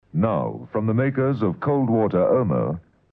You know you're listening to a Springbok Radio programme by the distinctive voices of the announcers that can be heard at the top and tail of each episode...
There are three versions of this pre-recorded announcement heard in the surviving episodes.